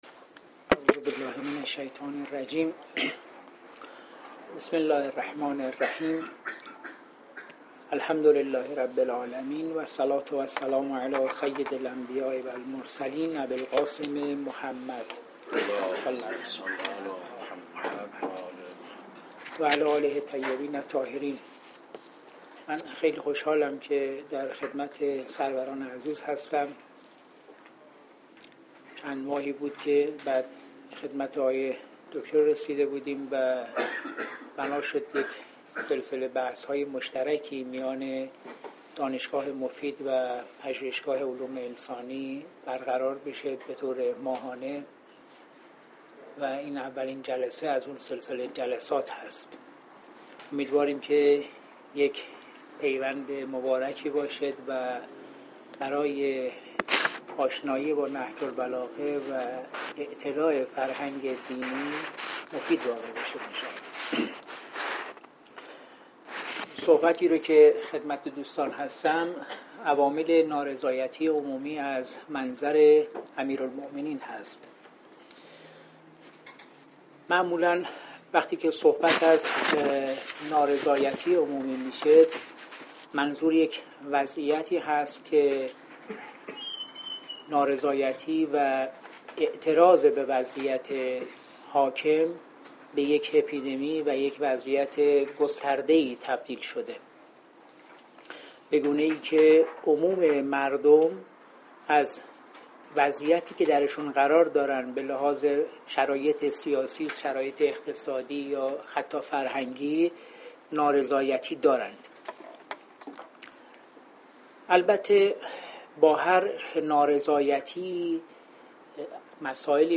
مکان: سالن اندیشه